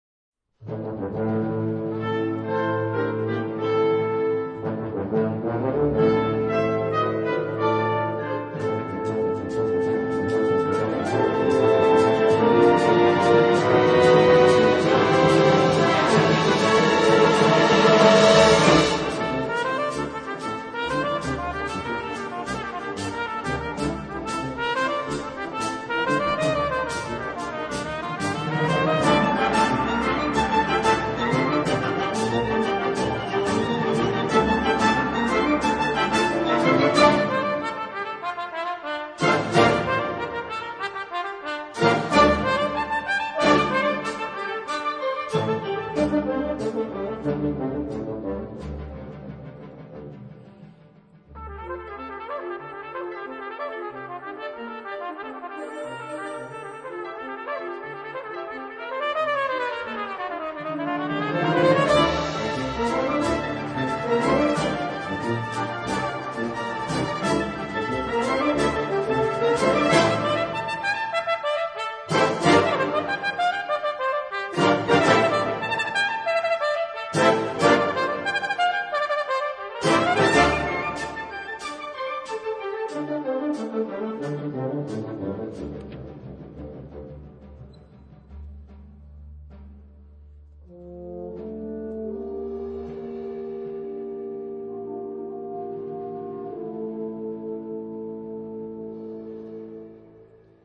這是管弦樂與小號的合奏，2016年於首爾首演且錄音。
這是現代作品，相當精彩！